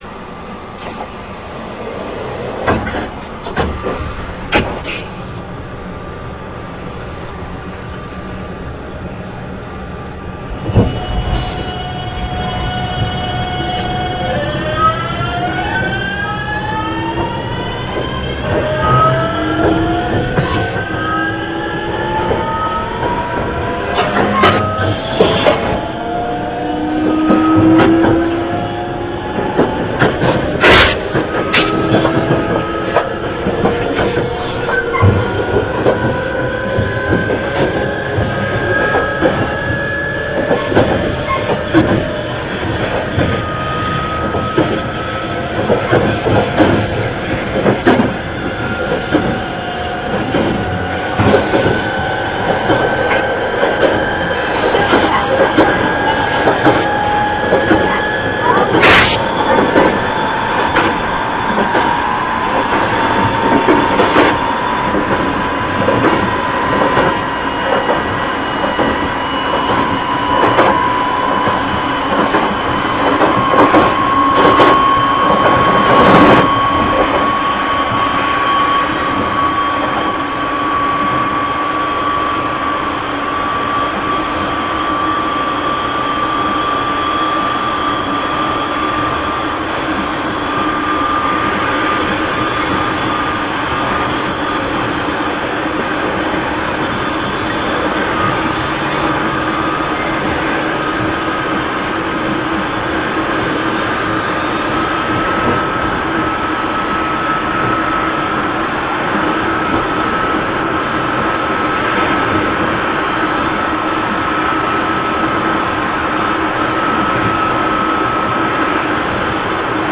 JR四国8000系(S6編成・東芝)[jrs8000a.ra/354KB/RealAudio5.0形式]
この形式のブレーキは発電ブレーキのみで回生ブレーキを積んでいないため、減速時の音はとてもつまらないです。(^^;　しかも、東芝と東洋の編成とで音に変わったところがほとんど見当たらないです。掲載している中で変わっているのはS6編成で、東芝モーターを積んでいますって音ですね。(^^　しかし、ほとんど個体差なのではないかなと思います。
◆量産車S6編成：「いしづち14号」(「しおかぜ20号」併結)伊予三島→川之江